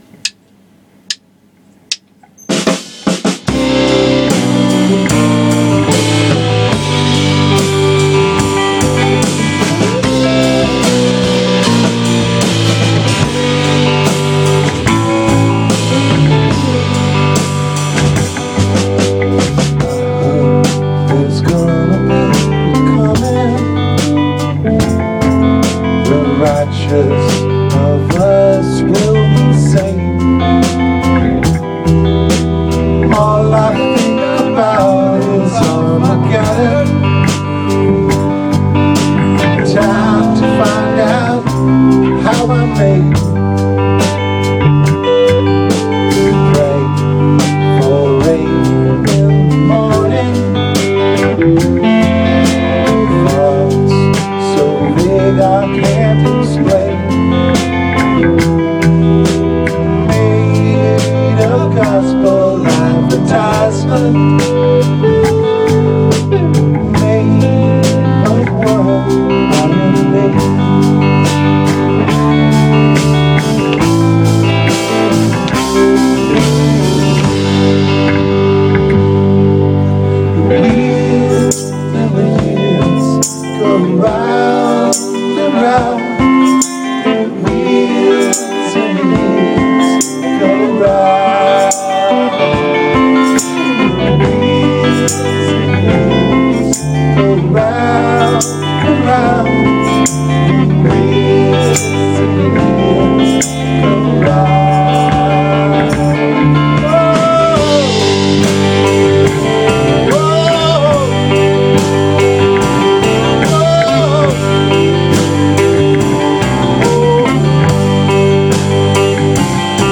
(w guit)